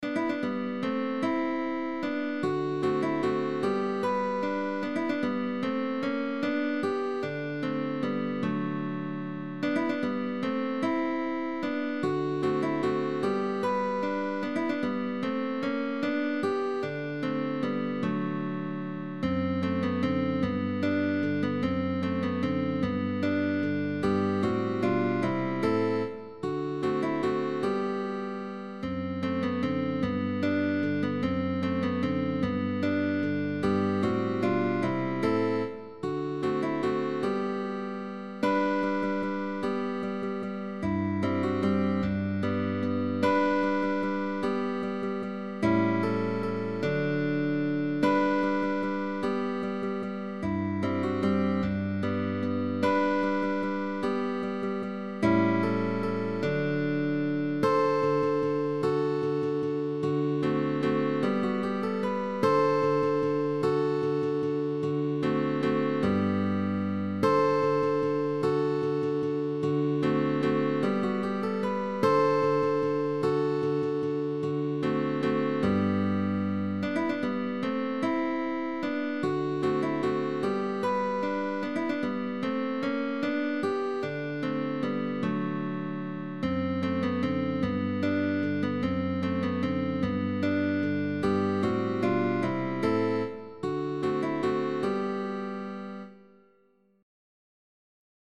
Notas simultáneas en cuerdas adyacentes.